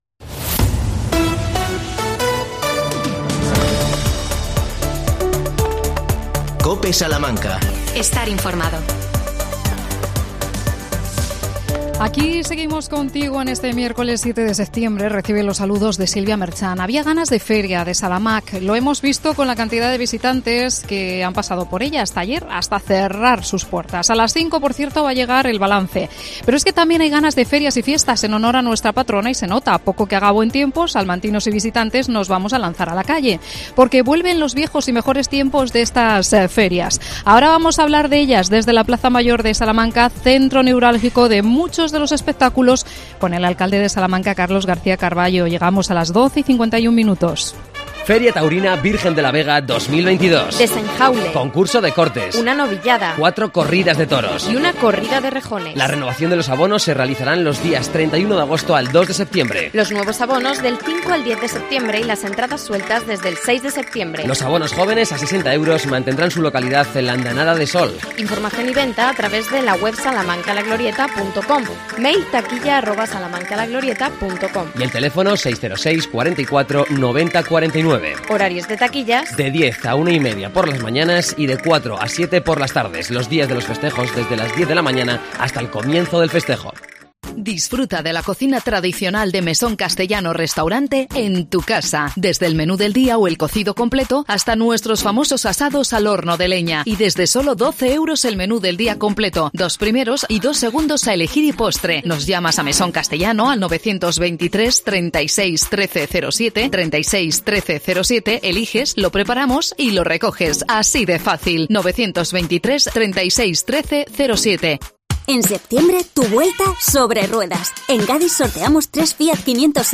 COPE SALAMANCA entrevista, en la Plaza Mayor, al alcalde de Salamanca. Carlos García Carbayo felicita las Ferias a los salmantinos y visitantes.